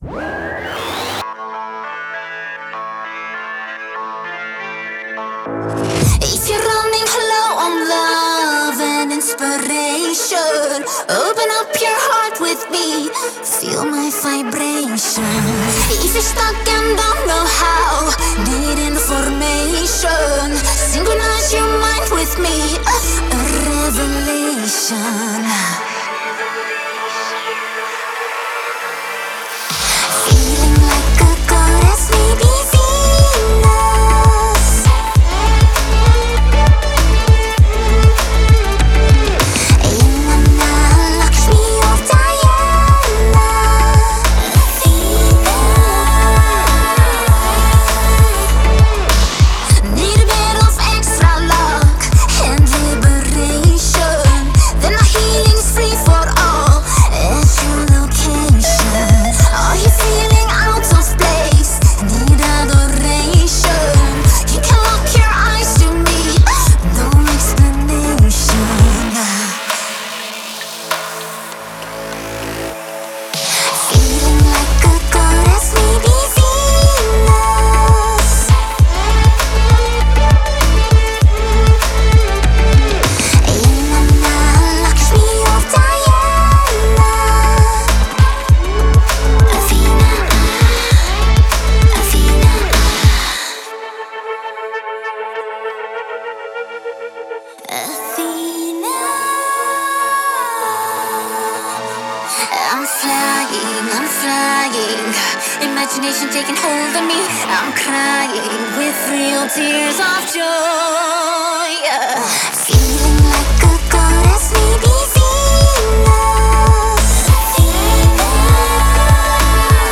99 Electronic Dance
electronic music artist